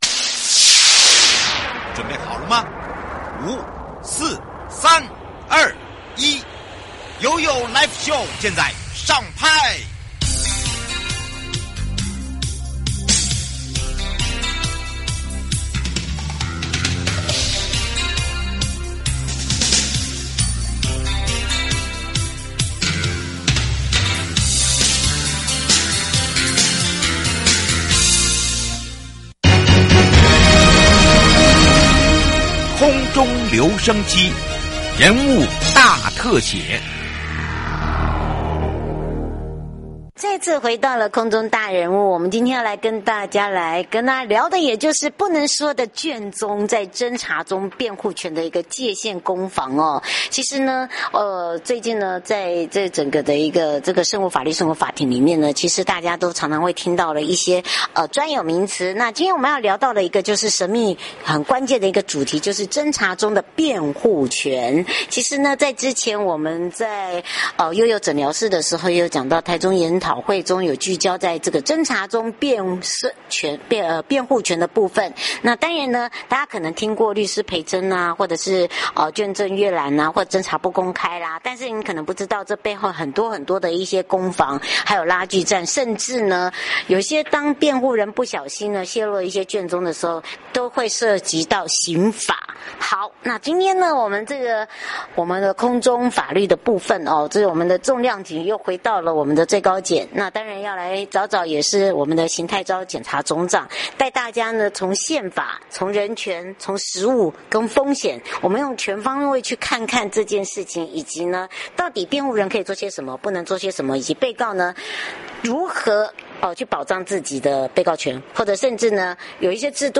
受訪者： 最高檢察署 邢泰釗檢察總長 節目內容： 主題：《不能說的卷宗？